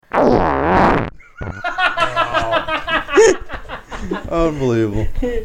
Tags: funny comedy podcast radio disgusting